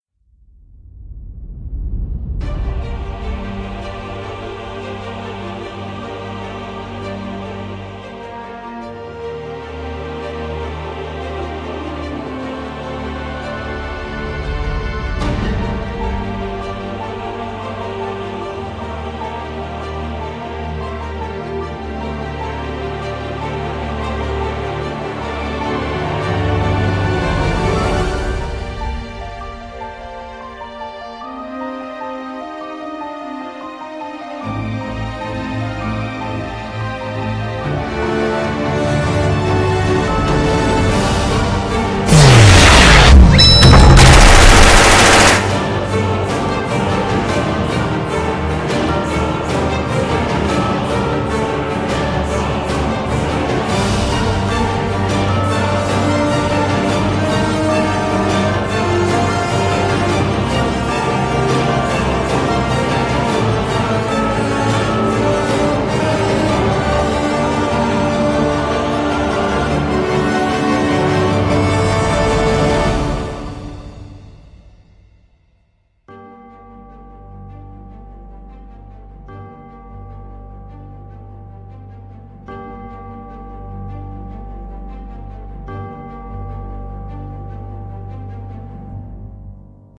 【声劇】竜猪の闘志